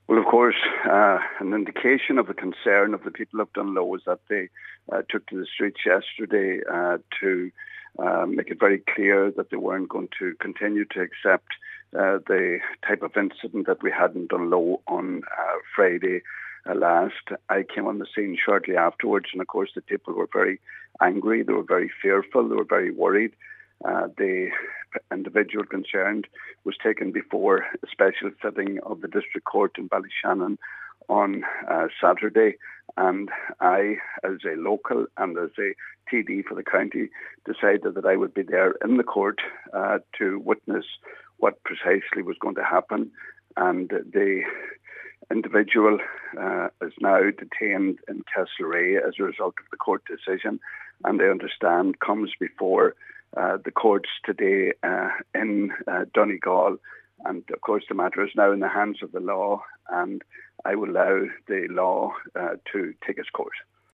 Donegal Deputy Pat the Cope Gallagher says the incident is now in the care of the authorities: